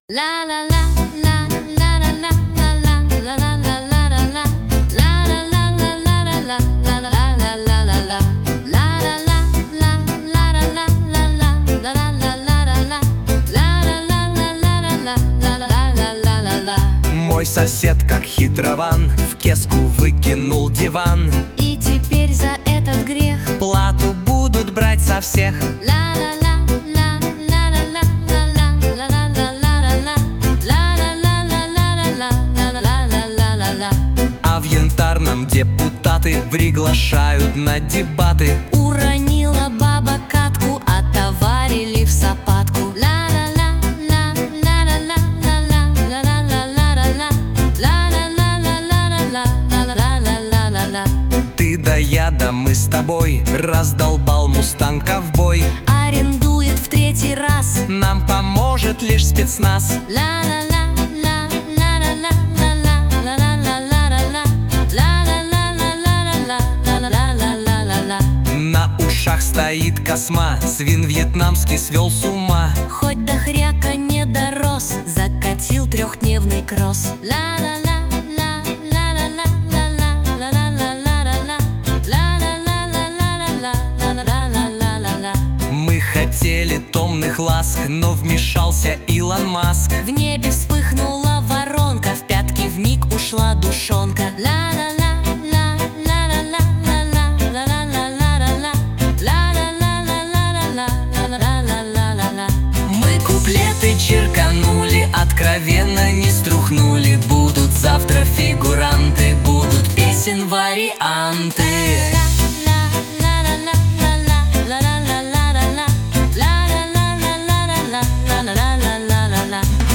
Комические куплеты о главных и важных событиях